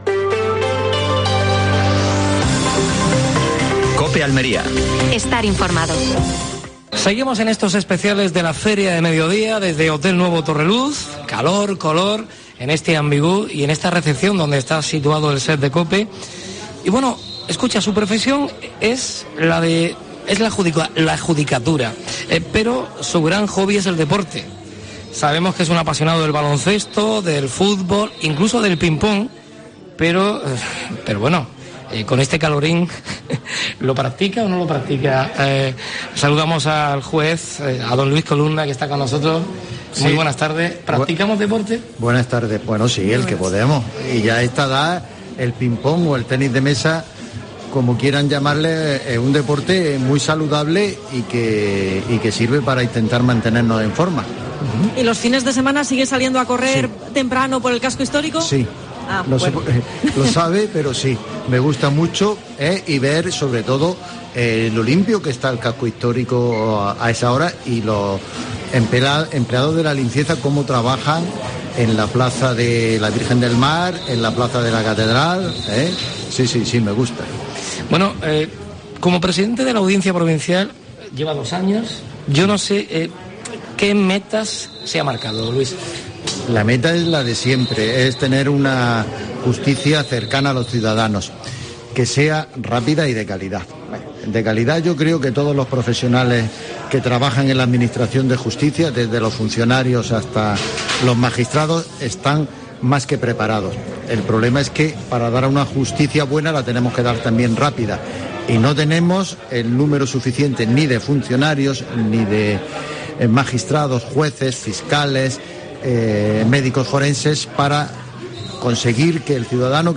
AUDIO: Último programa especial de la Feria de Almería. Desde el Hotel Torreluz. Entrevista al juez Luis Columna.